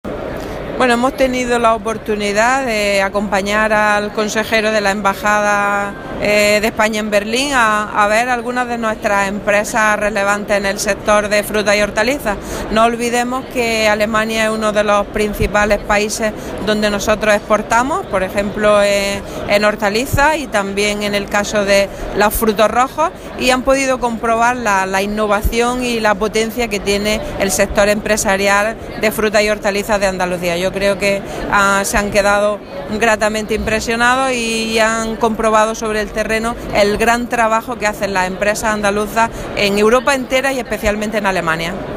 Declaraciones de Carmen Ortiz sobre visita a estands andaluces en Fruit Logística junto a miembros de la Embajada Española en Berlín y exportaciones hortofrutícolas a Alemania